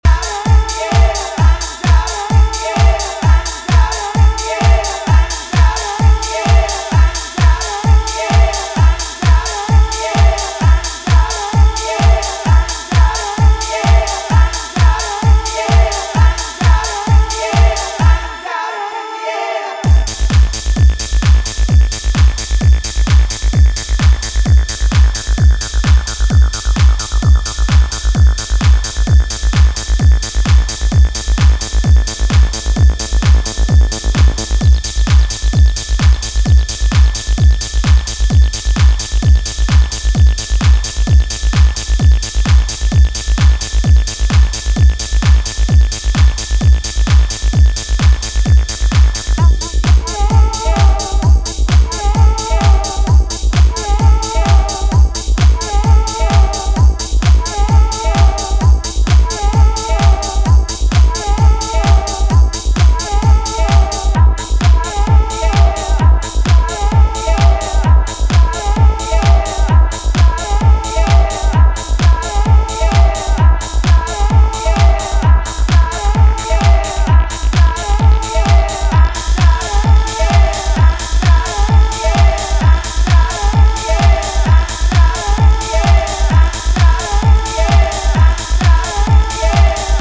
疾走するアシッド・ハウス